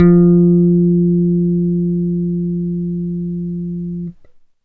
11-F.wav